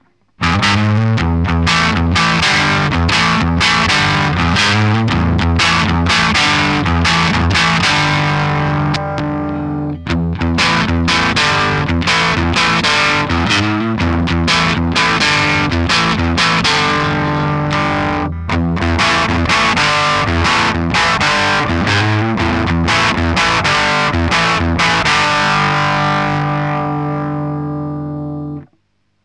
quelques samples pour te faire une idée (sortie line avec un cut des aigus pour simuler rapidement un cab)
fuzz_manche-centre-chevaldouble.ogg